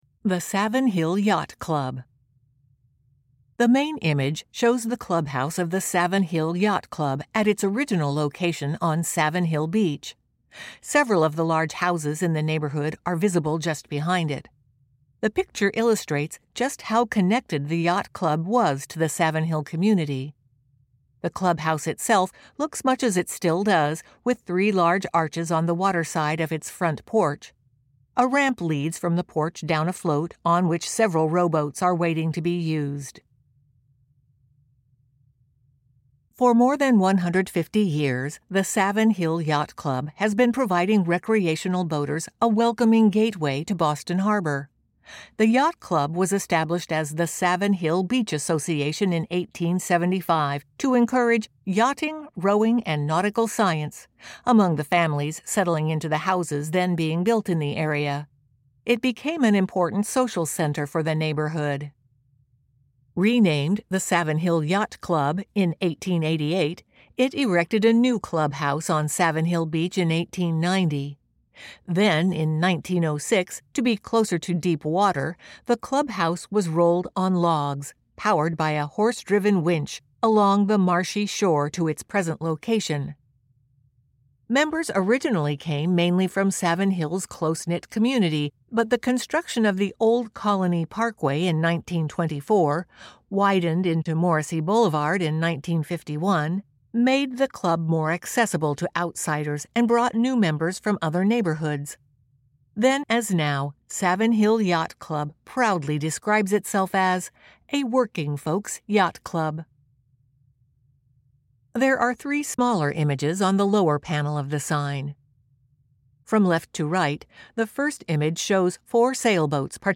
Our gratitude to the Perkins School for the Blind Recording Studio